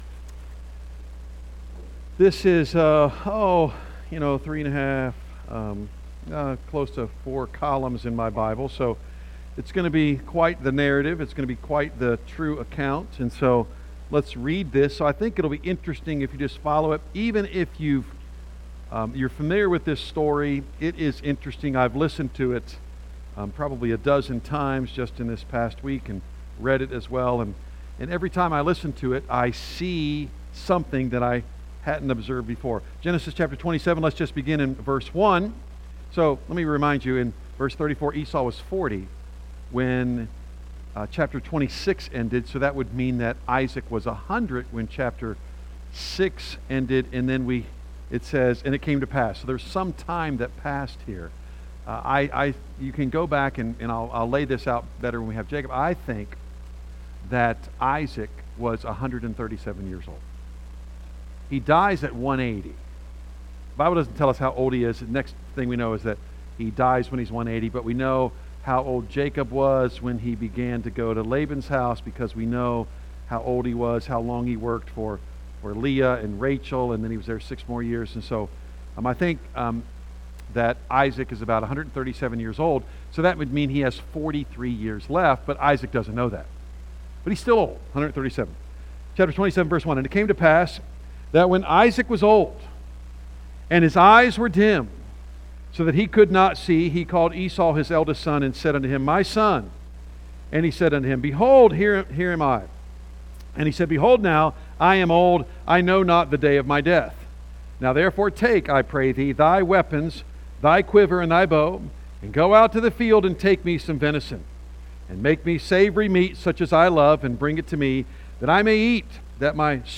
A message from the series "Genesis."